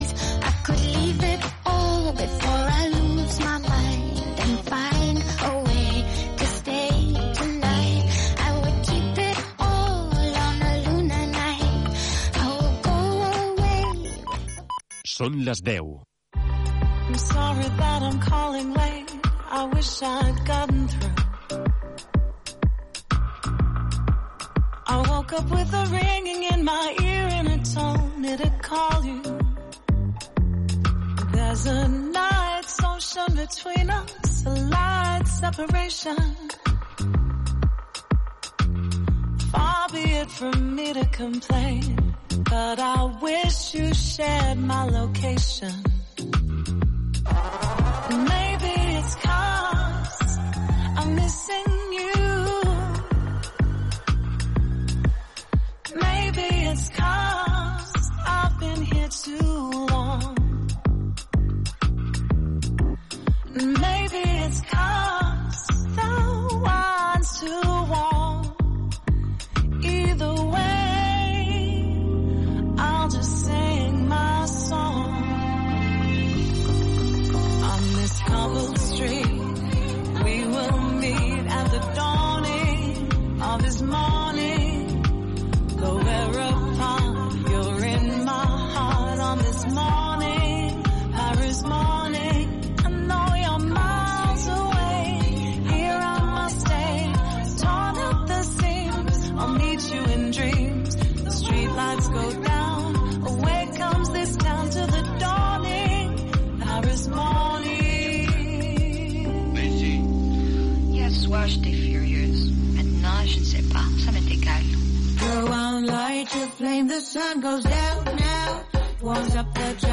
Flamenquejant es una nueva ventana que se asoma al flamenco más variado, una propuesta en la que vas a encontrar un poco de todo desde el flamenco más tradicional y clásico hasta lo más nuevo y actual.